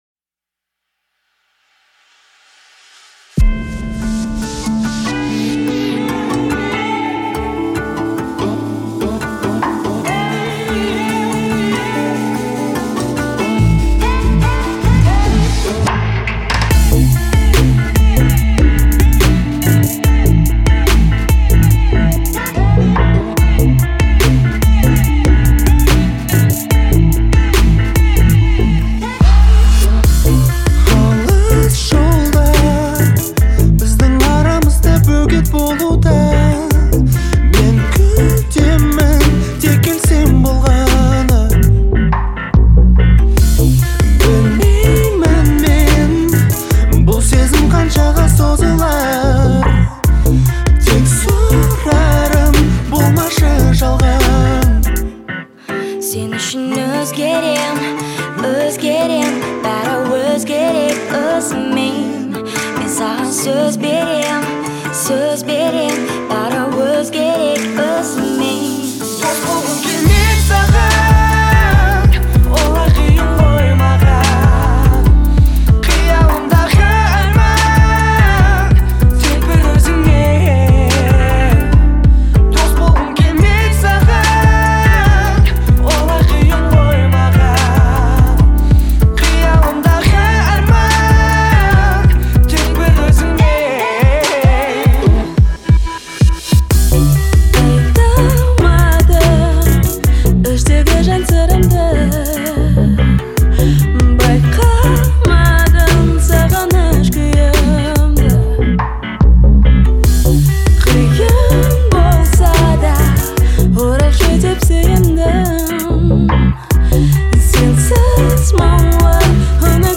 яркая и энергичная песня казахской а капелла группы